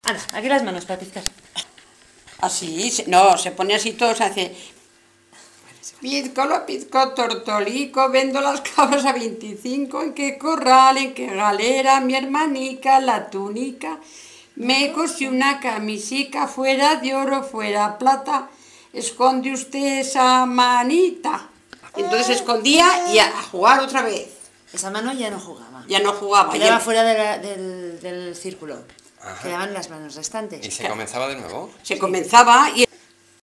Cancioncillas infantiles y juveniles